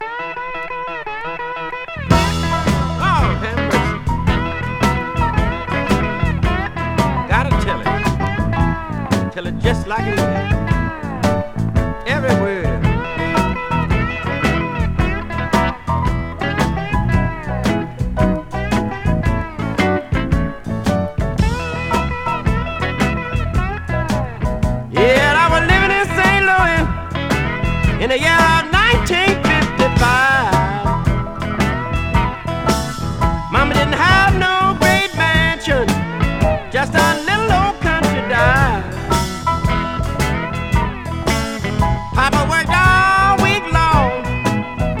Rock, Rock & Roll　USA　12inchレコード　33rpm　Stereo